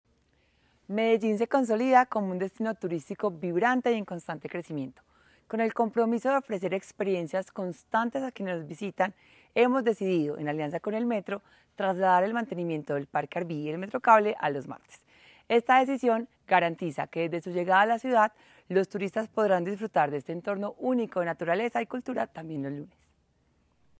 Declaraciones